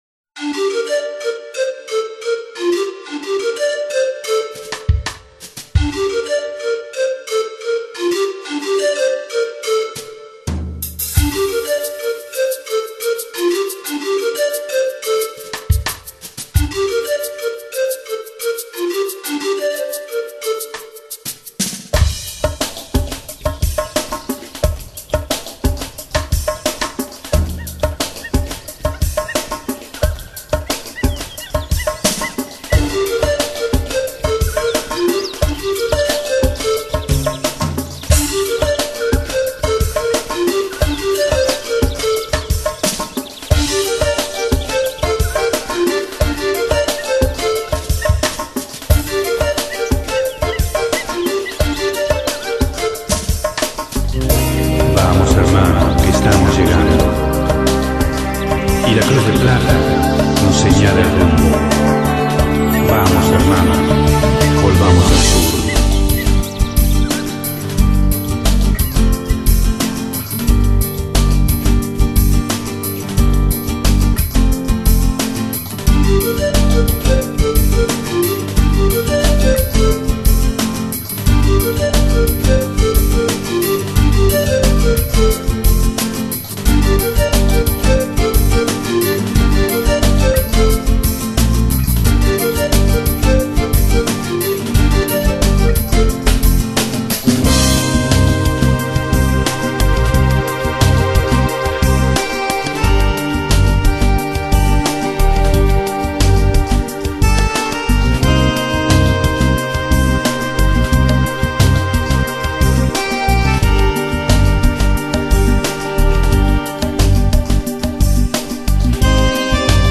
Music Genre: Chillout - Lounge - Downtempo